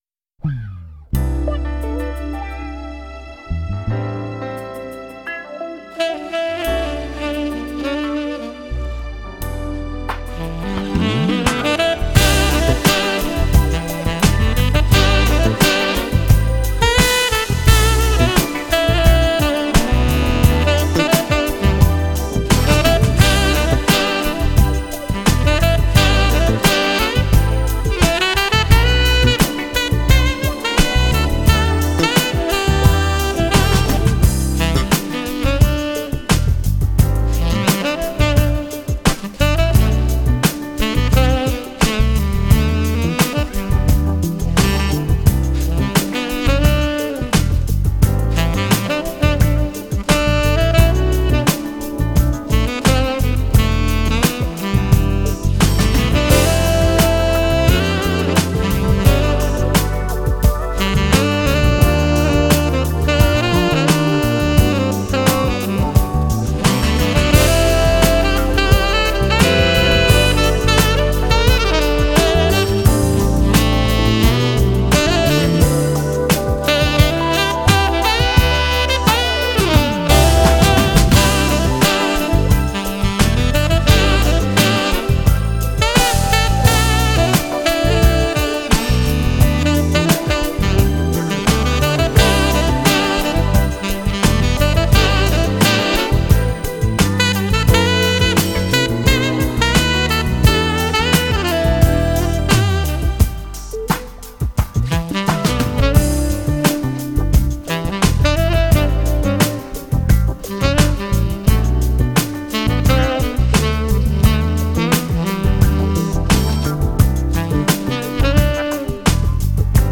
makes me want to dance like my life depended on it